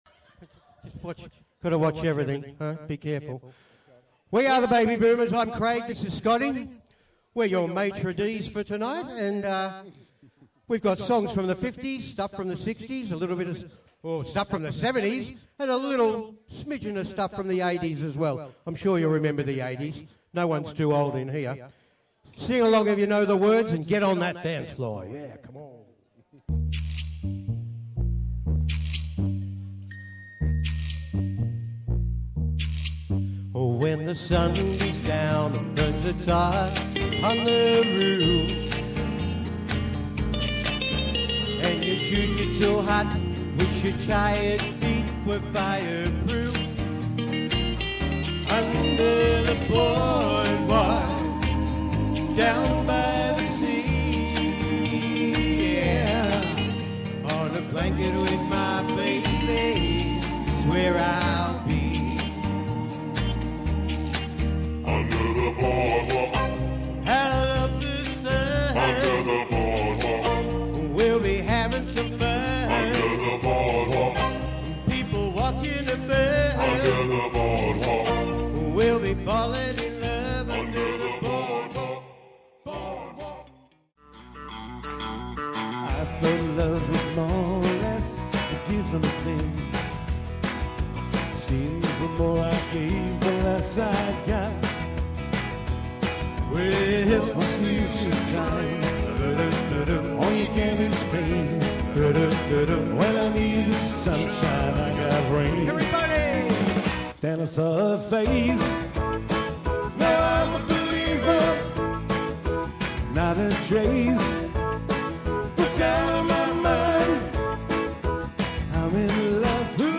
fabulous vocal harmonies
classic hits & fun filled performances